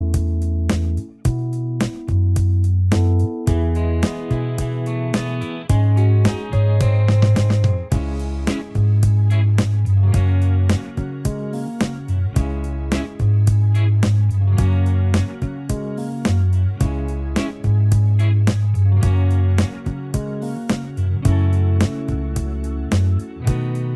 Minus Acoustics Soft Rock 5:06 Buy £1.50